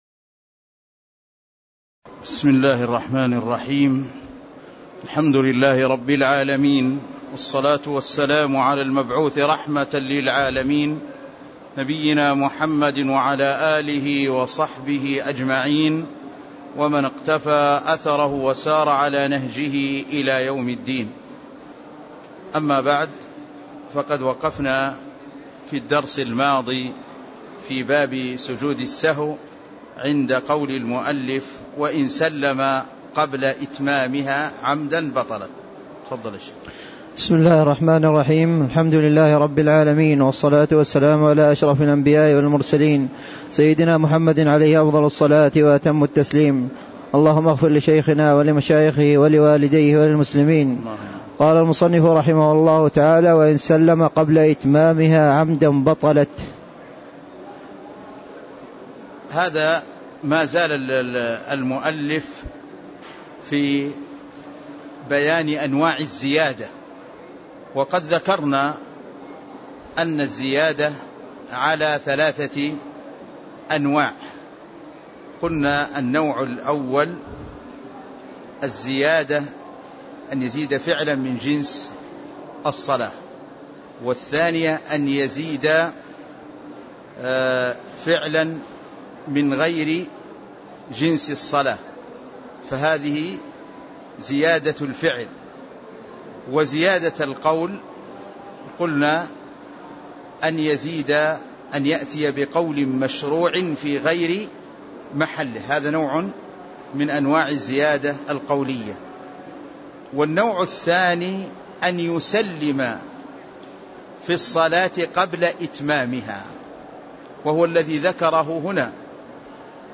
تاريخ النشر ١١ محرم ١٤٣٦ هـ المكان: المسجد النبوي الشيخ